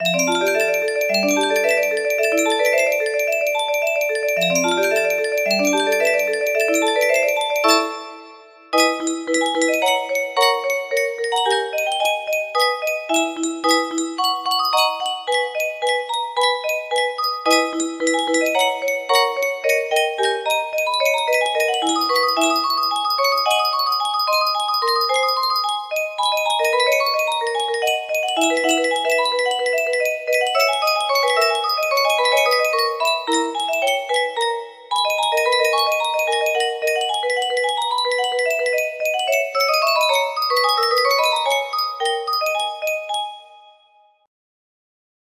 Grand Illusions 30 (F scale)
BPM 110